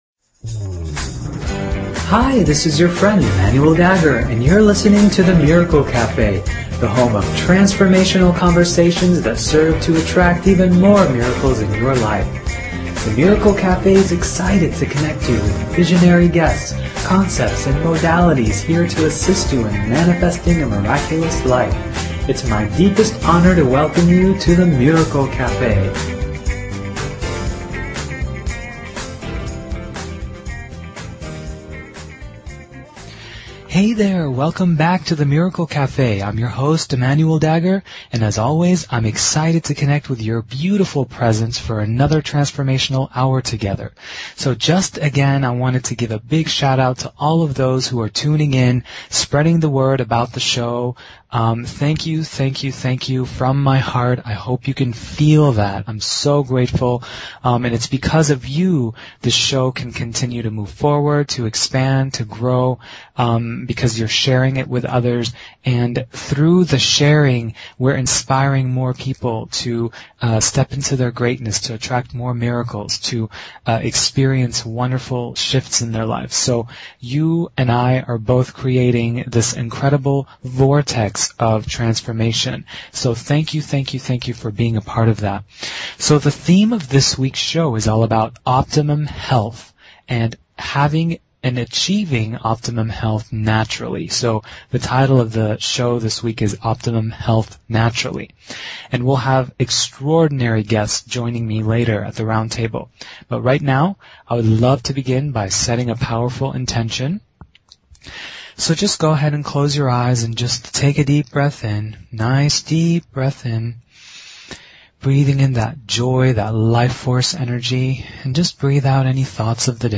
Talk Show Episode, Audio Podcast, The_Miracle_Cafe and Courtesy of BBS Radio on , show guests , about , categorized as
The Miracle Café is a radio show that is here to provide each listener with life-changing insights, processes, and conversations that serve to attract even more miracles and positive transformation in their life!